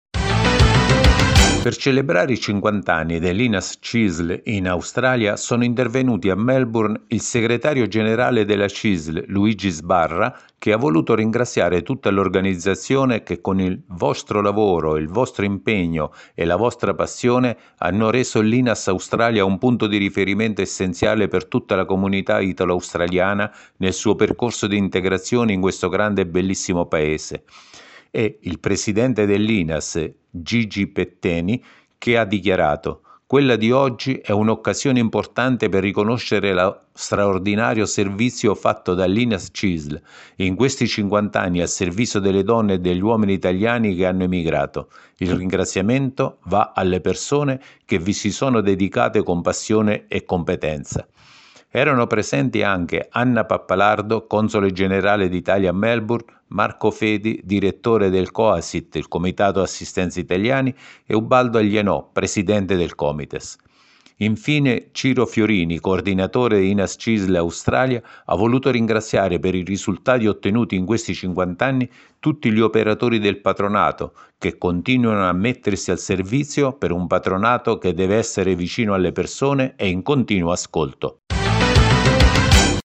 In Australia l’Inas-Cisl compie 50 anni (audio notizia)